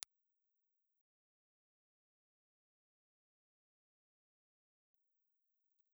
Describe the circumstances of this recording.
Impulse Response file of Melodium RM6 ribbon microphone in 0.1m position Melodium_RM6_HPF_0.1_IR.wav